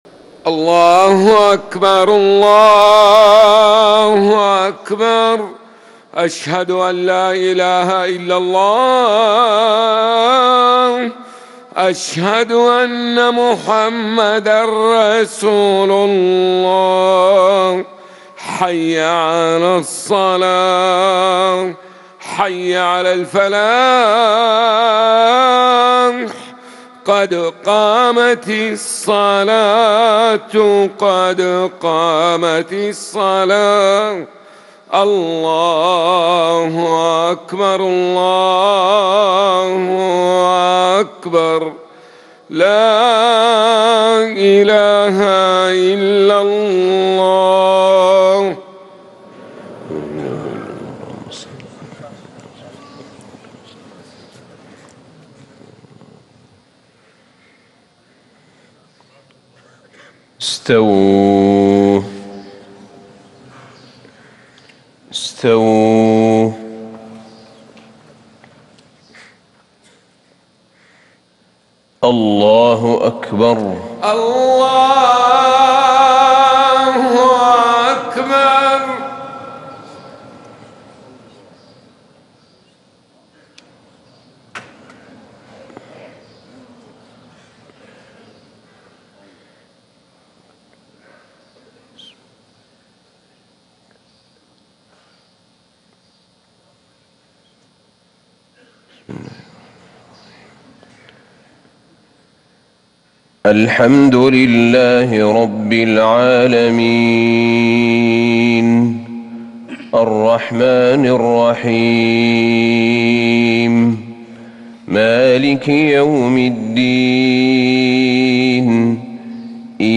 صلاة الفجر 2-6-1440هـ سورة ق | Fajr 7-2-2019 prayer from Surat Qaf > 1440 🕌 > الفروض - تلاوات الحرمين